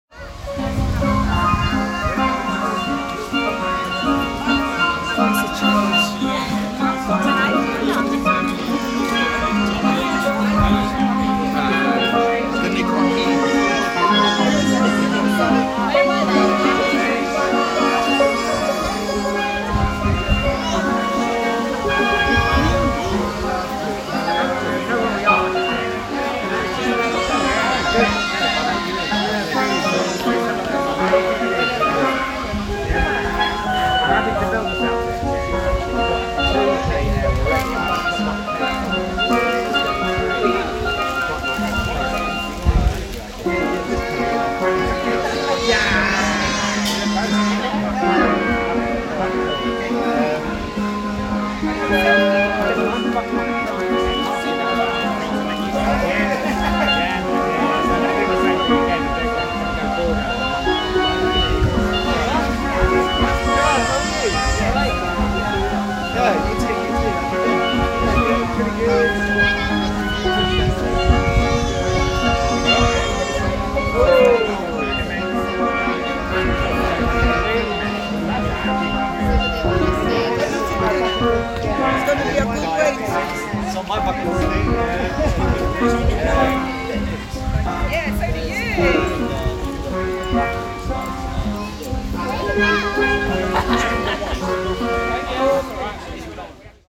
It's a key part of the multicultural hub of Notting Hill, with people from many different countries, backgrounds and cultures sharing the space. In this recording, a steel band is performing on a Sunday afternoon.